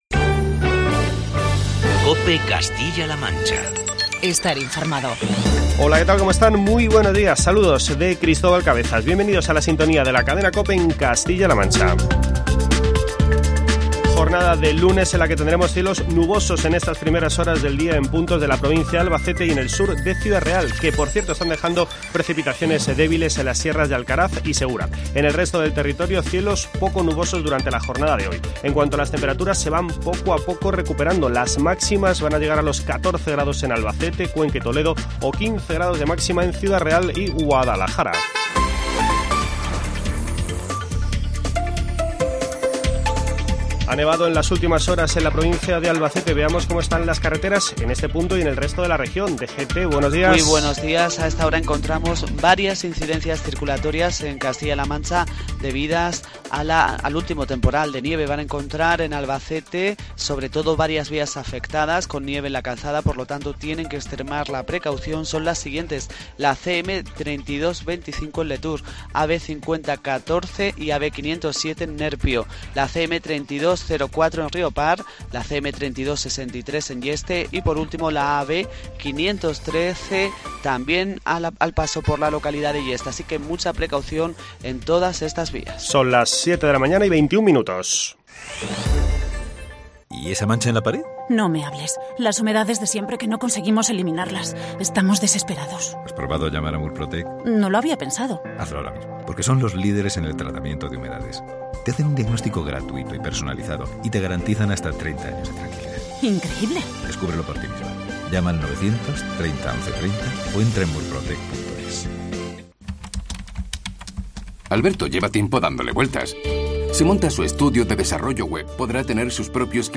AUDIO: Toda la actualidad en los informativos matinales de la Cadena COPE.